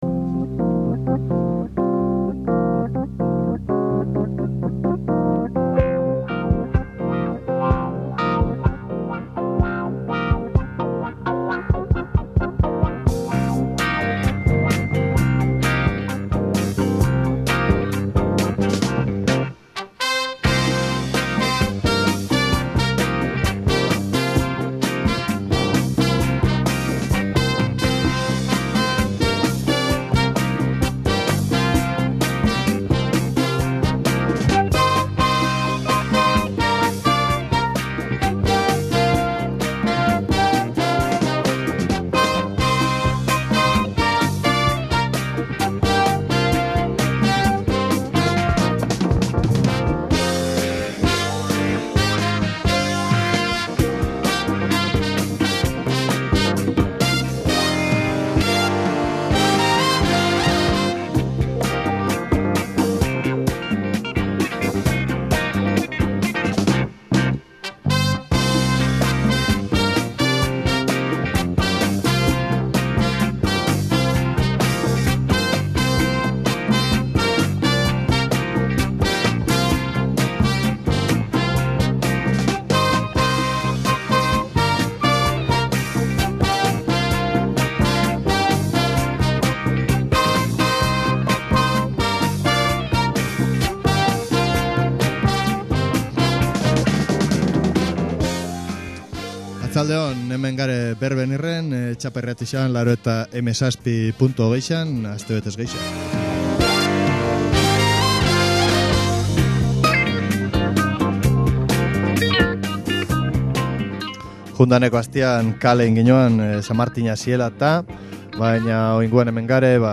Rock-etik soul-era, hainbat estilo ezberdin.